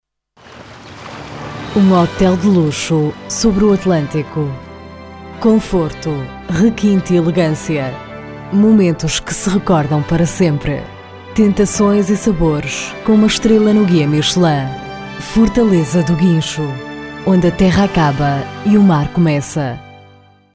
Portuguese European Female Voice
Sprechprobe: Werbung (Muttersprache):